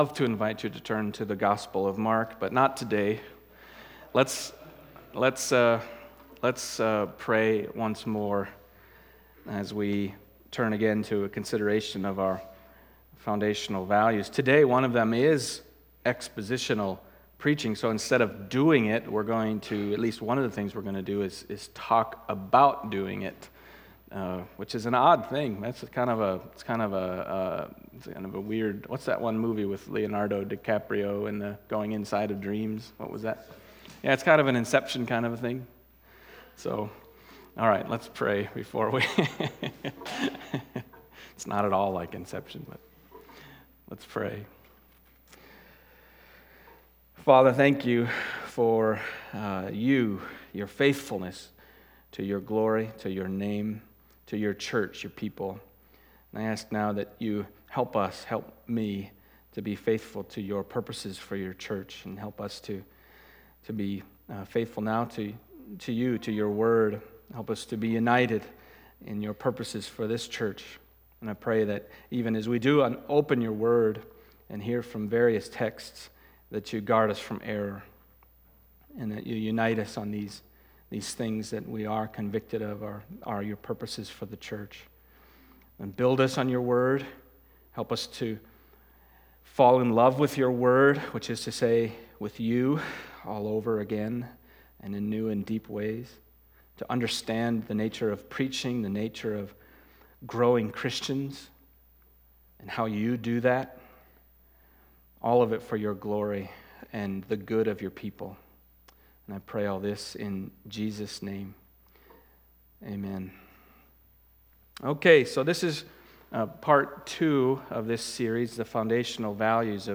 Foundational Values Service Type: Sunday Morning 2.